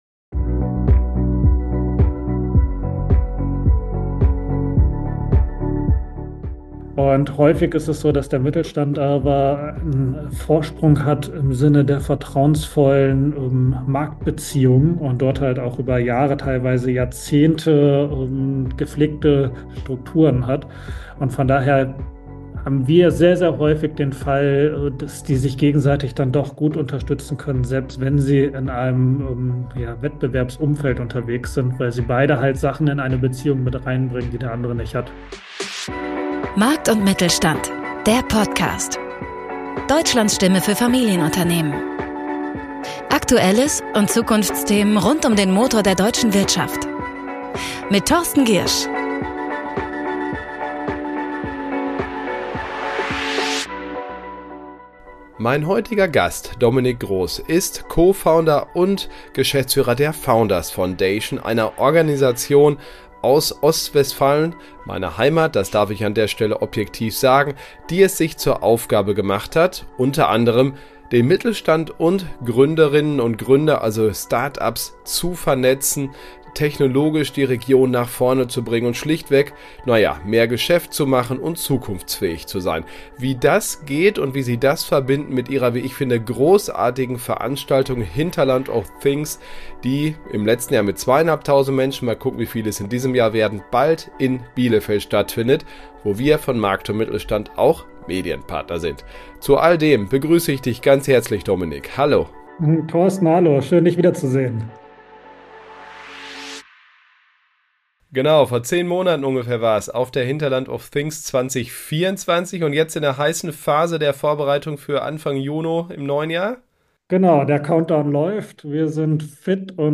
Jeden Freitag bieten wir effektiv in rund 15 Minuten zweierlei: Erstens stellen wir kurz und smart zusammen, was in dieser Woche Relevantes aus Sicht eines Mittelständlers passiert ist. Und zweitens sprechen wir mit einem Gast zu einem für den Mittelstand wichtigen Thema – hoher Nutzwert garantiert!